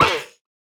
Minecraft Version Minecraft Version 1.21.4 Latest Release | Latest Snapshot 1.21.4 / assets / minecraft / sounds / mob / armadillo / hurt3.ogg Compare With Compare With Latest Release | Latest Snapshot
hurt3.ogg